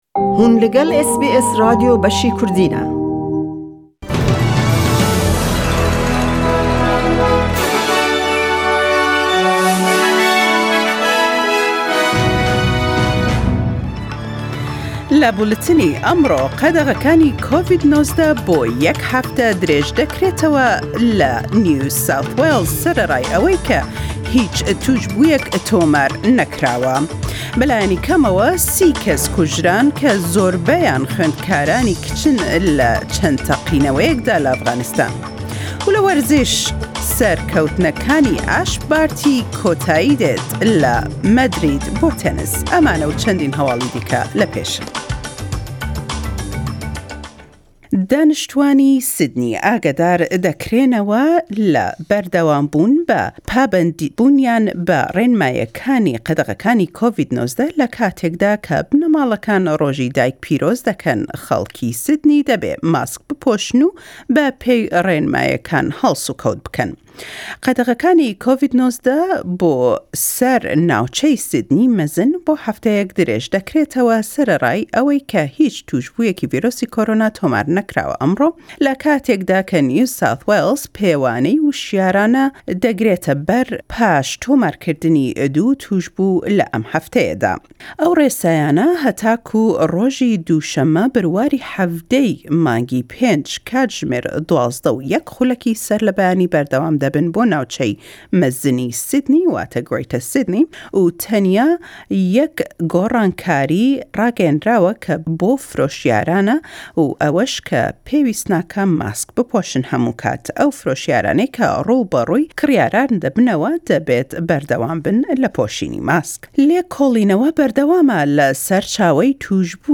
SBS Kurdish news for weekend of 08-09 May 2021.